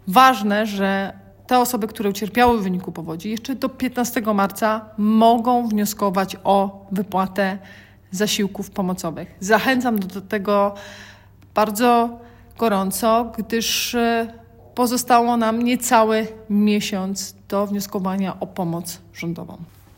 Wojewoda dolnośląska Anna Żabska zachęca mieszkańców, którzy jeszcze nie złożyli wniosku, do jak najszybszego skorzystania z tej możliwości.